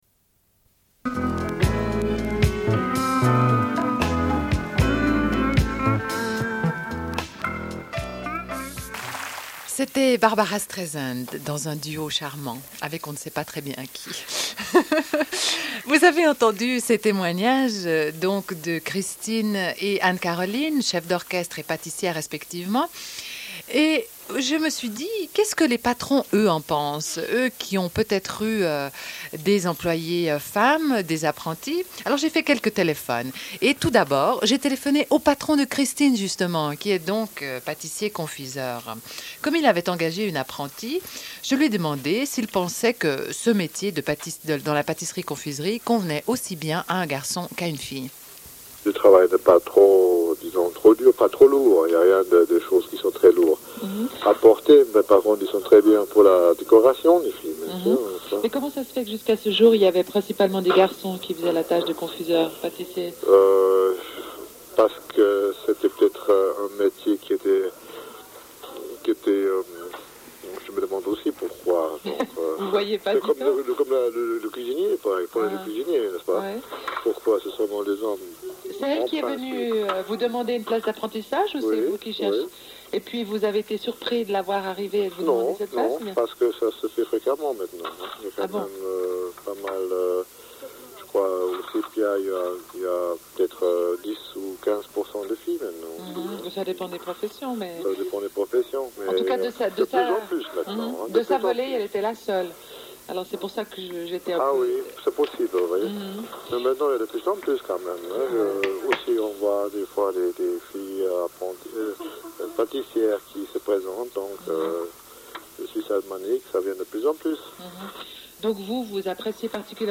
Une cassette audio, face A00:30:28